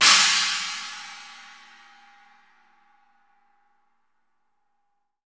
-17 CHINA.wav